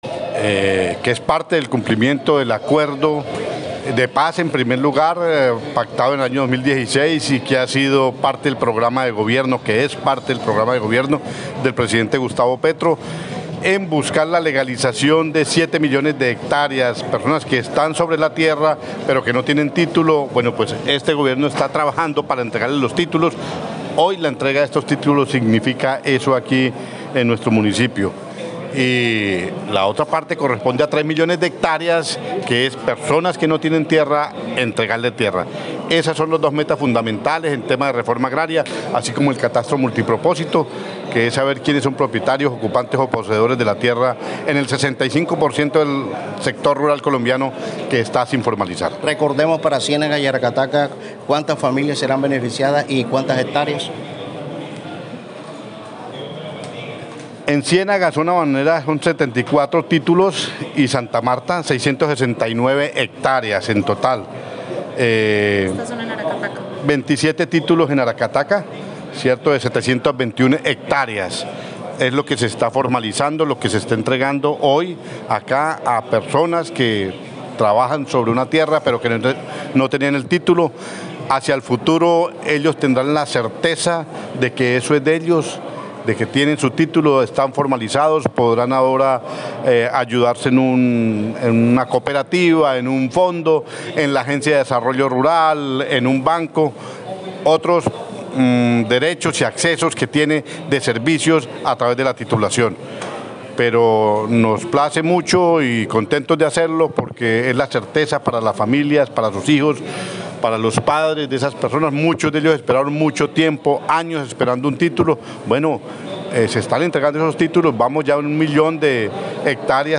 De esa manera lo afirmó hoy el director general de la Agencia Nacional de Tierras – ANT, Gerardo Vega Medina, durante el acto de entrega de títulos en Ciénaga, Magdalena.
ENTREVISTA